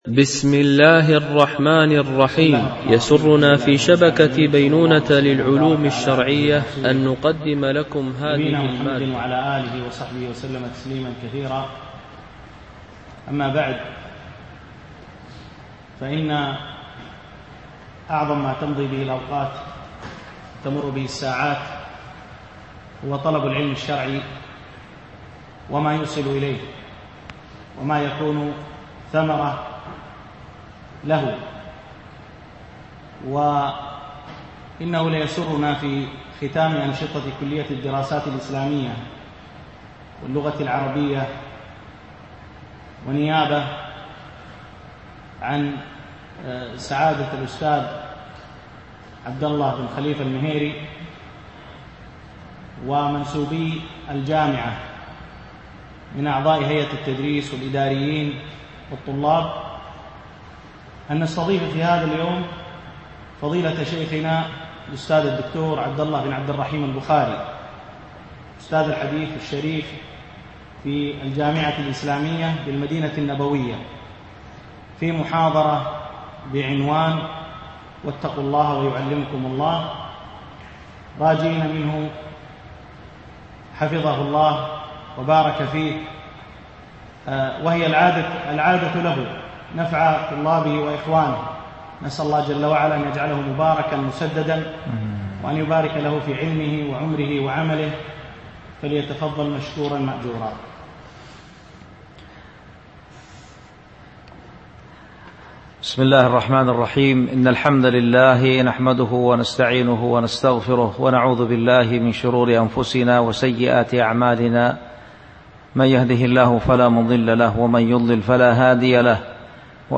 محاضرة: اتقوا الله ويعلمكم الله